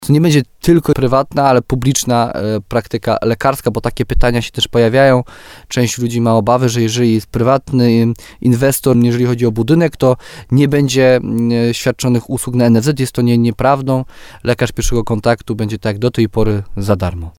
Wójt gminy Gródek nad Dunajcem Jarosław Baziak mówi, że trwają rozmowy z jednym z lekarzy-kardiologów, który jest zainteresowany budową przychodni Podstawowej Opieki Zdrowotnej.